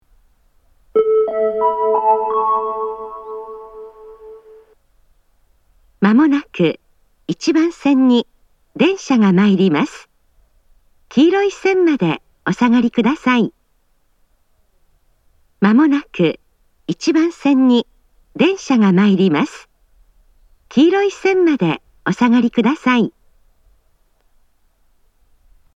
また、スピーカーが上下兼用なので、交換のある列車の場合、放送が被りやすいです。
自動放送
仙石型（女性）
接近放送